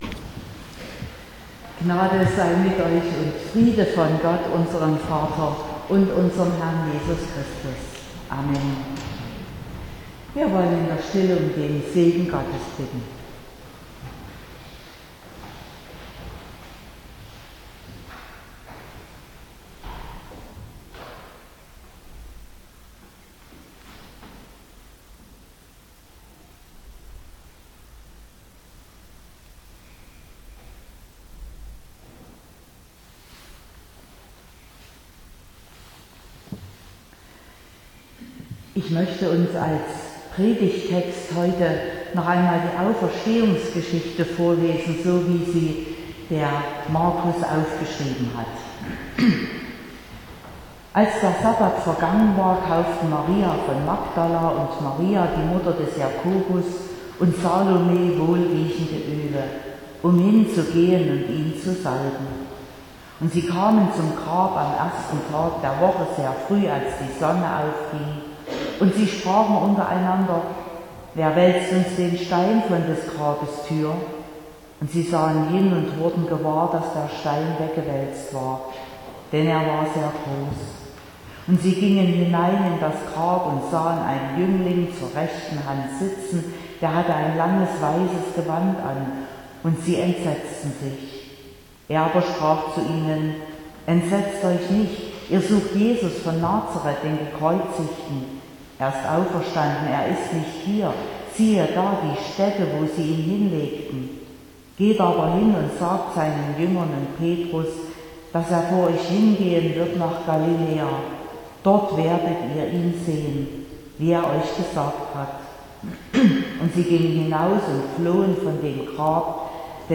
18.04.2022 – Gottesdienst
Predigt und Aufzeichnungen